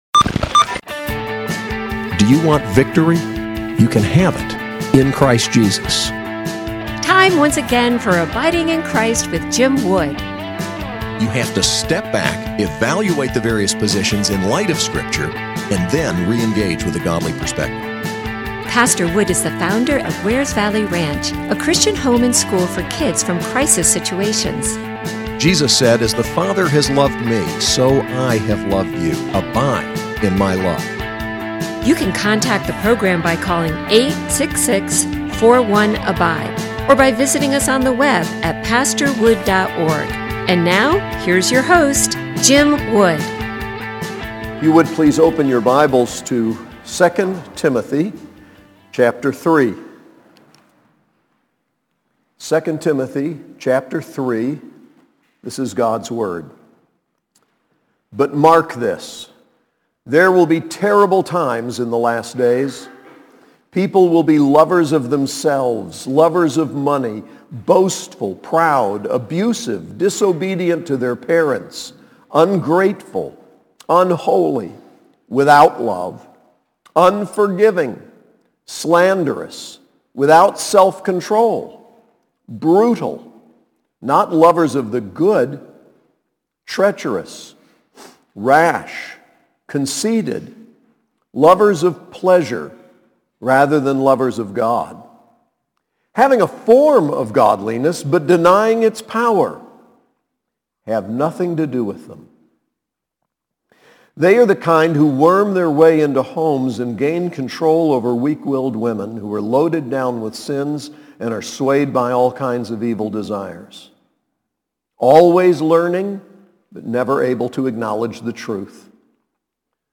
SAS Chapel: 1 Timothy 3